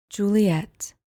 Pronounced: JOO-lee-ETT